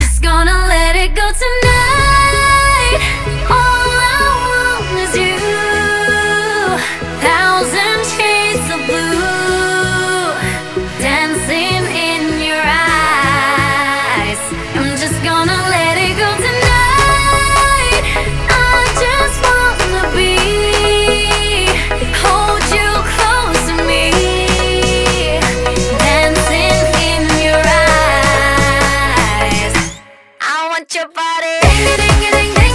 • Качество: 320, Stereo
поп
женский вокал
dance